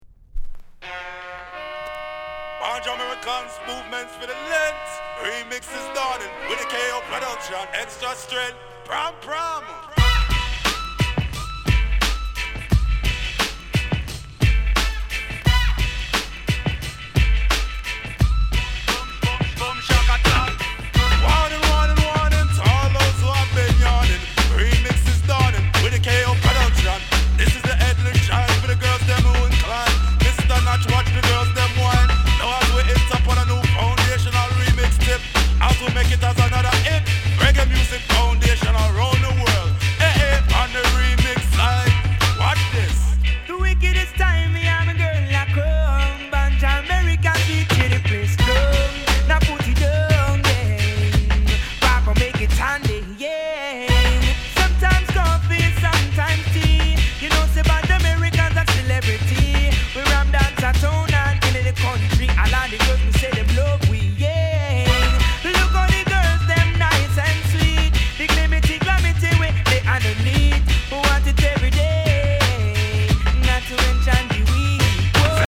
HIP HOP REMIX